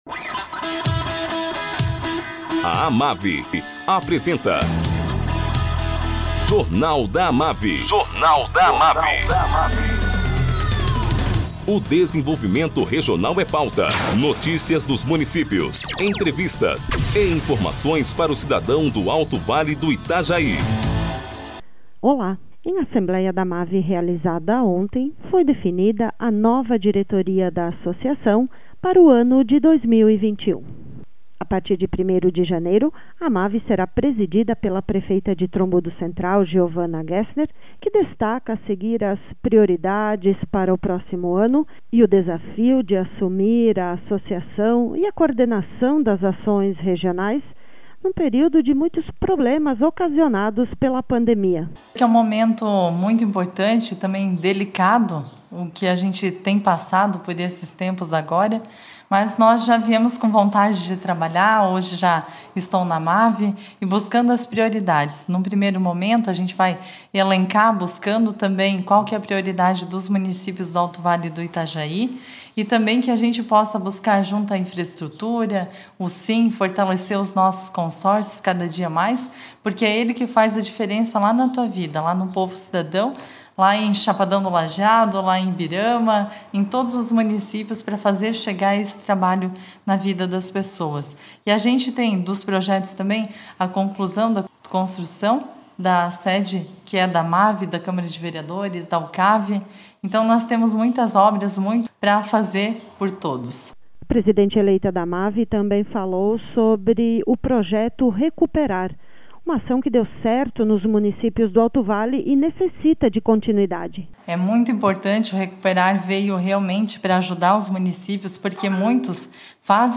Presideente eleita da AMAVI, Geovana Gessner, fala sobre as prioridades para 2021 e enfrentamento a pandemia.